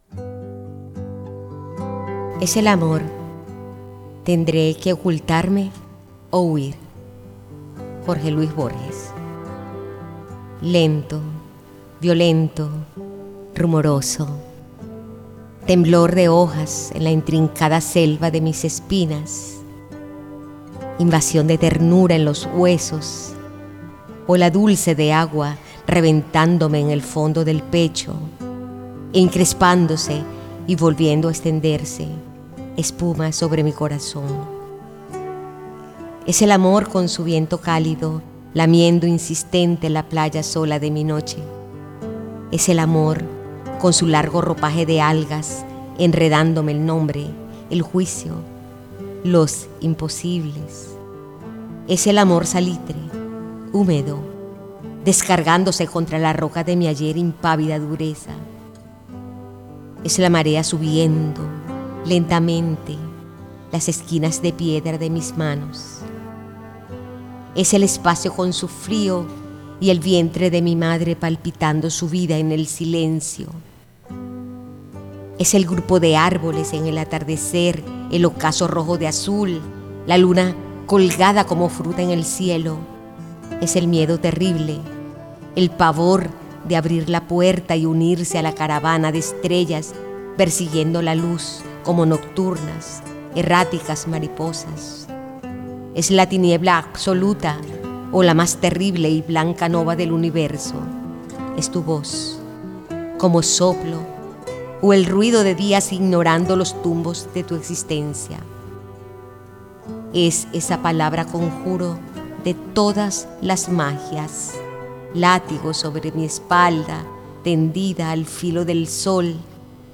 Aula de Letras al Aire es un programa de radio universitaria que lleva la literatura (poemas y relatos) más allá del aula, ofreciendo una experiencia auditiva y accesible para la comunidad universitaria y el público en general.